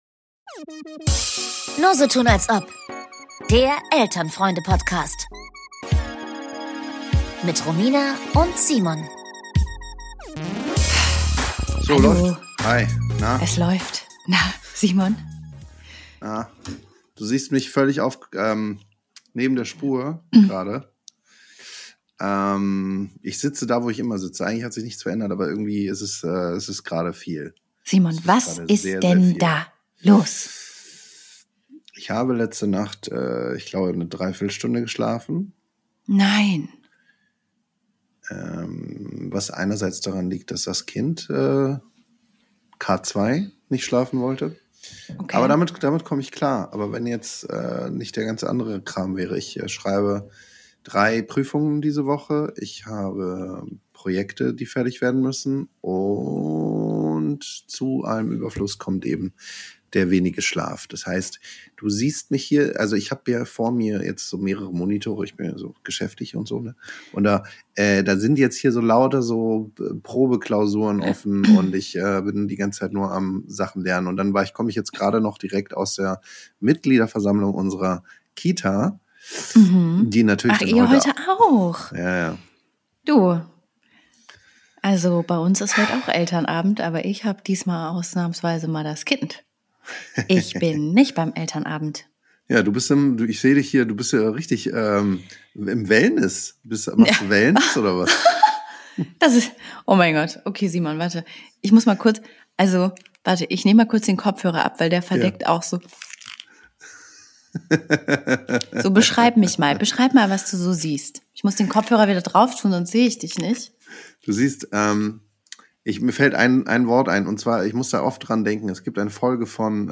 Mal wieder spät am Abend mit halber Gehirntätigkeit treffen sich zwei Freunde-Eltern zum digitalen Gespräch. Beide schlaflos, bald über das Coachella sinnierend über Klimakrisengespräche und kleinen Lichtblicken und am Ende geschmückt von uneitler Sinnlosigkeit.